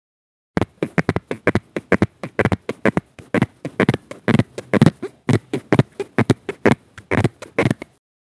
zvuk-trenija_006
zvuk-trenija_006.mp3